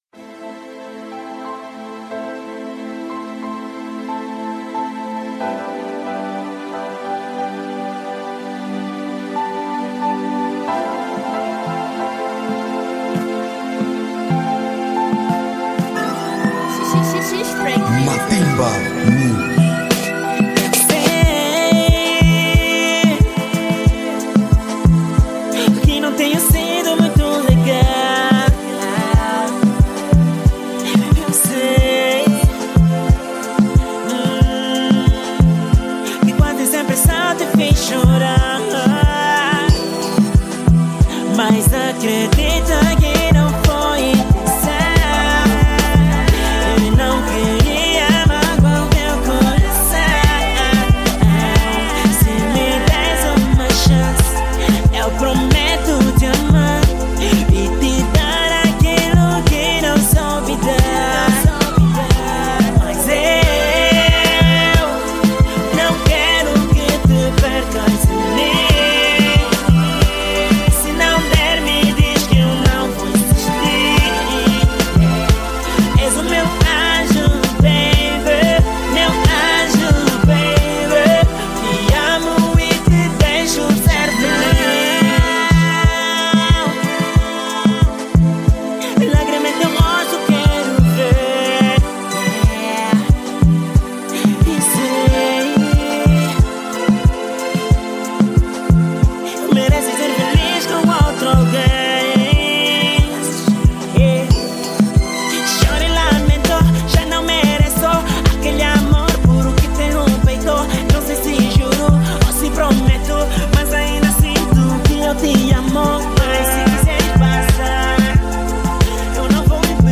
Género: Zouk